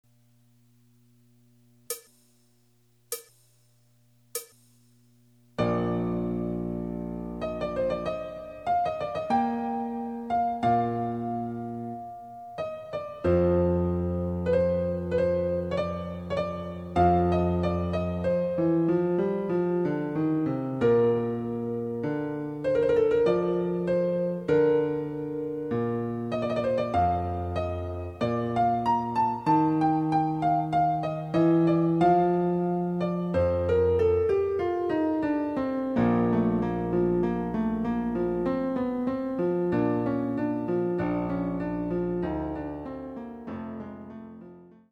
デジタルサンプリング音源使用
※ヴァイオリン奏者による演奏例は収録されていません。